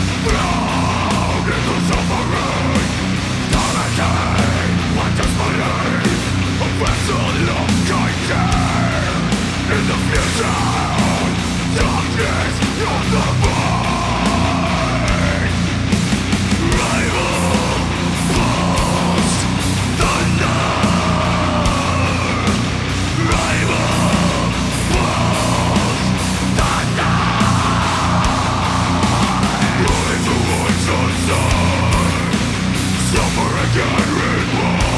Жанр: Рок / Метал
Death Metal, Black Metal, Rock